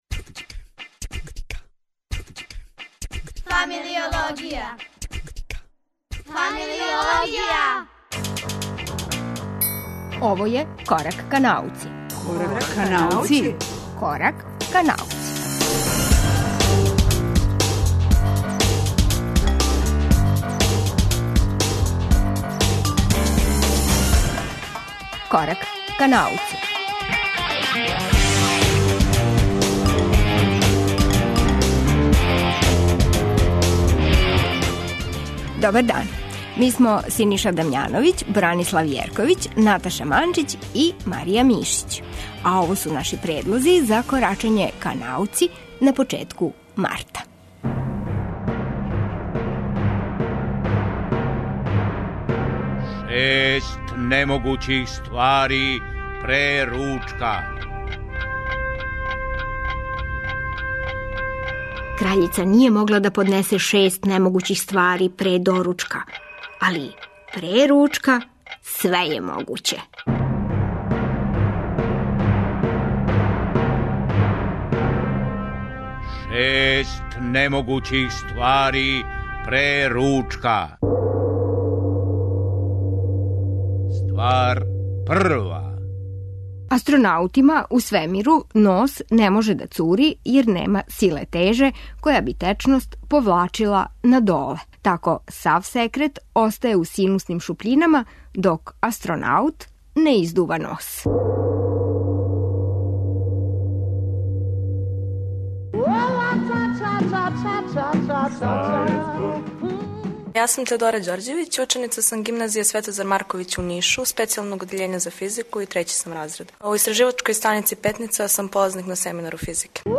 'Шест немогућих ствари пре ручка' комбинује се са пет разговора, односно, пет рубрика.